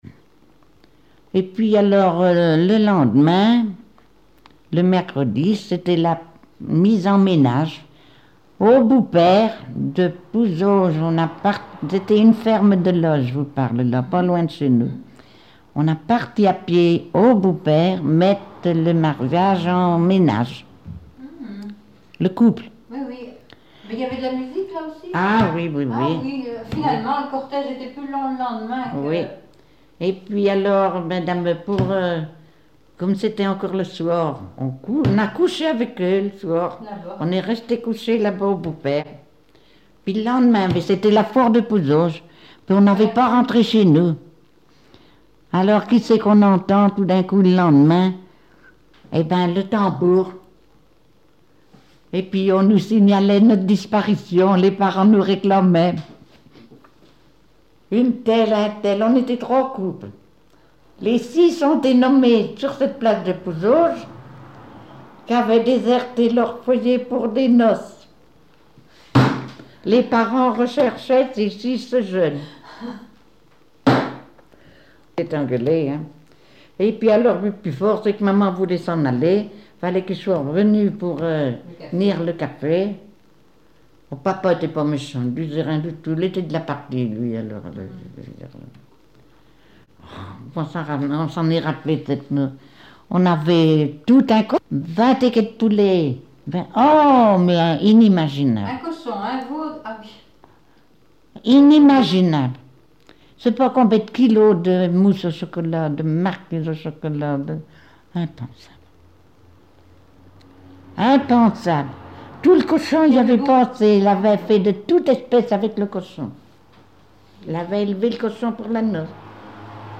Témoignage des débuts de couturière et quelques chansons
Catégorie Témoignage